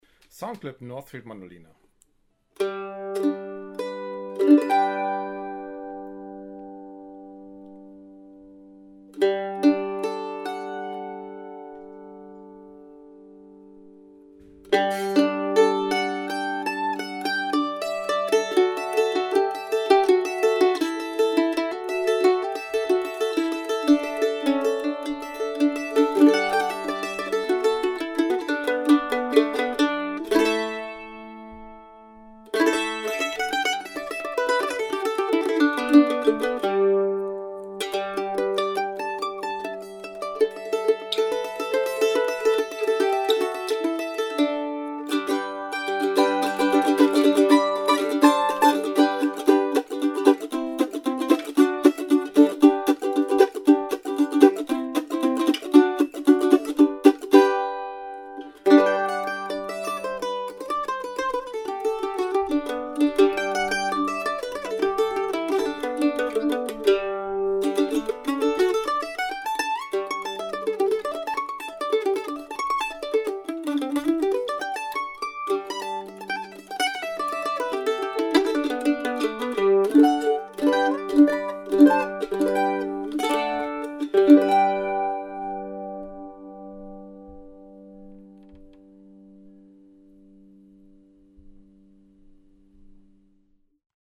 SOUND CLIPS - MANDOLIN